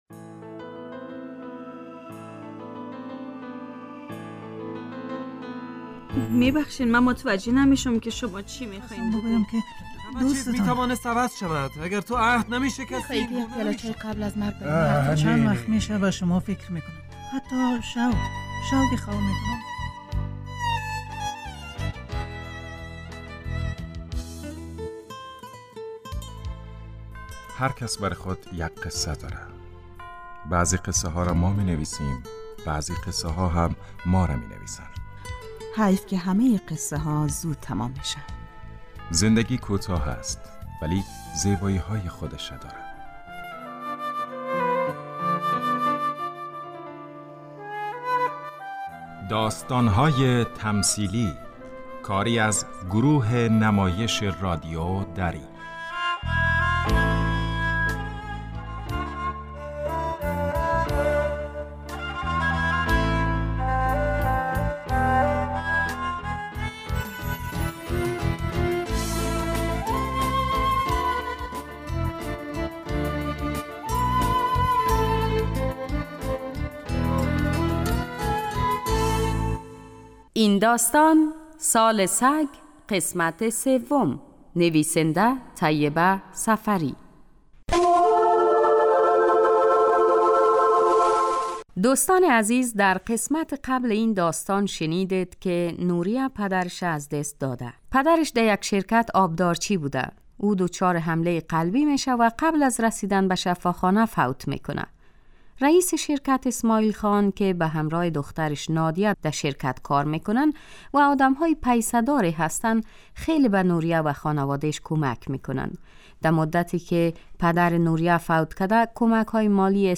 داستان تمثیلی / سال سگ